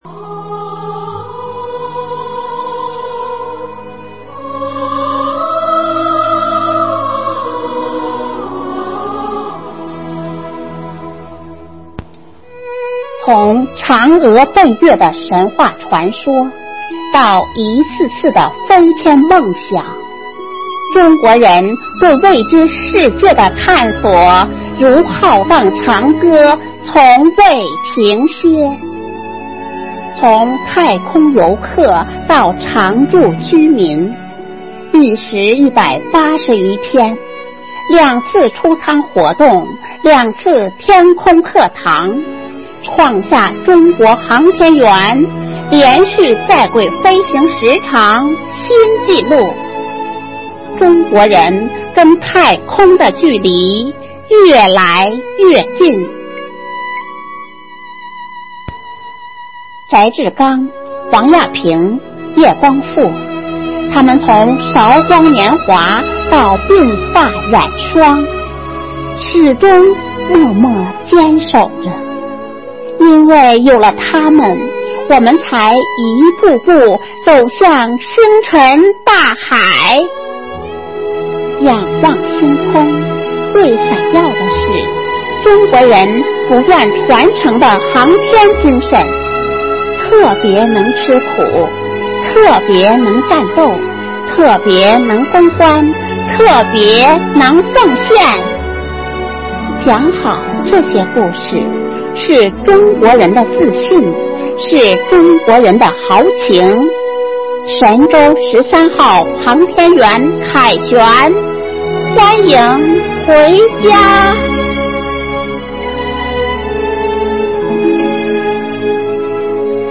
暨中华诗韵支队第13场幸福志愿者朗诵会